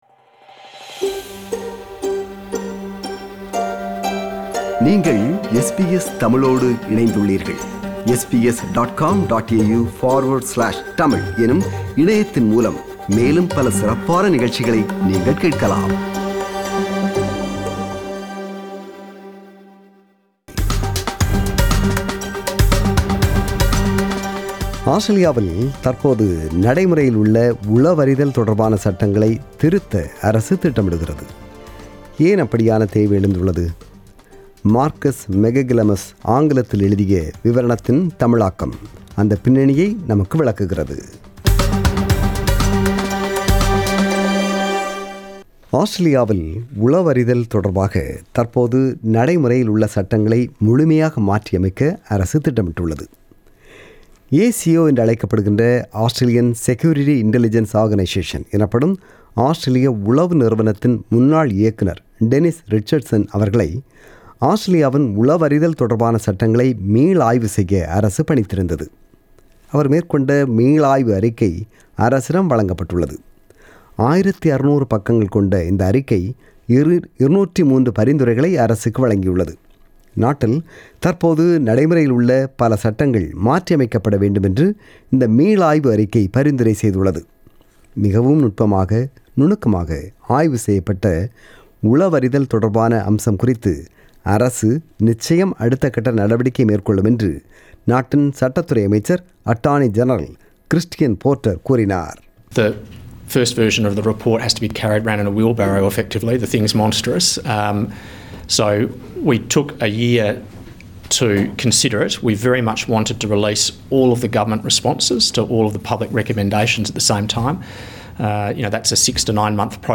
விளக்குகிறது இந்த விவரணம்.